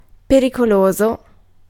Ääntäminen
Vaihtoehtoiset kirjoitusmuodot (vanhahtava) redoubtable Ääntäminen France: IPA: /ʁə.du.tabl/ Haettu sana löytyi näillä lähdekielillä: ranska Käännös Ääninäyte Adjektiivit 1. pericoloso {m} Suku: f .